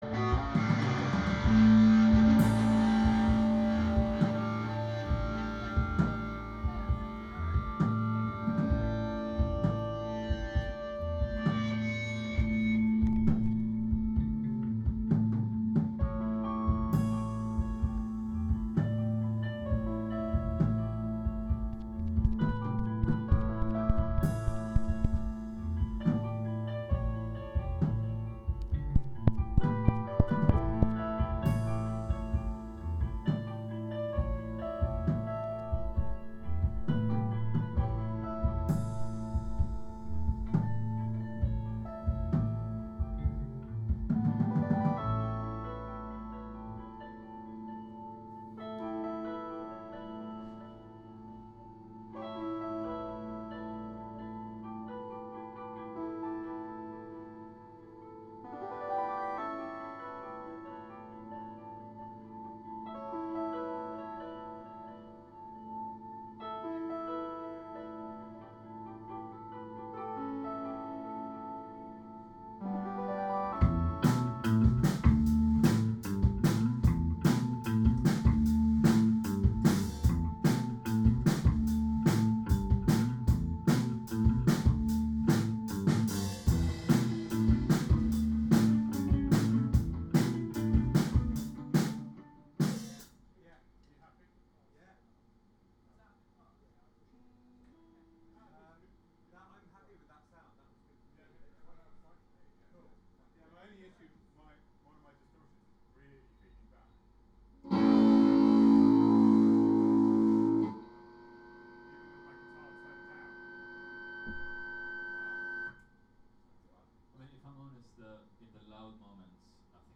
Live from Soundcamp: Sonic Frontiers (Audio) Jun 22, 2023 shows Live from Soundcamp The Sonic Agent presents sounds and music coming from the Southwest Asia and North Africa.